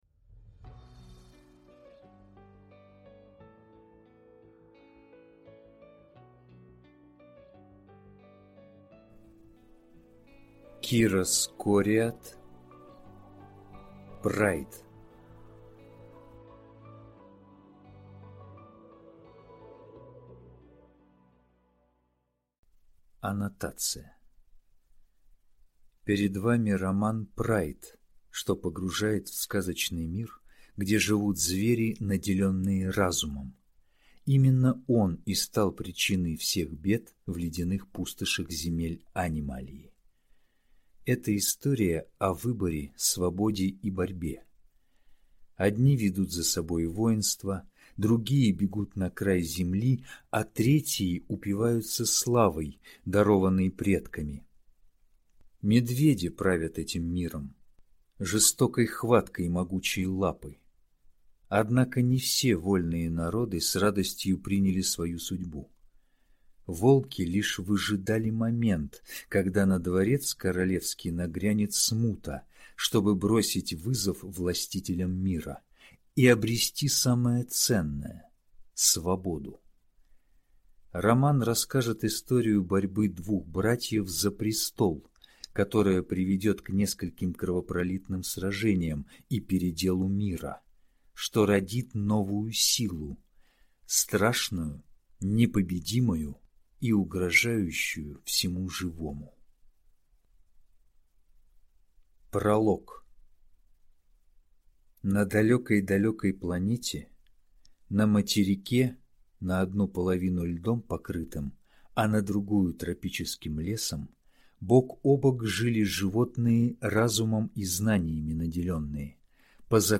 Аудиокнига Прайд | Библиотека аудиокниг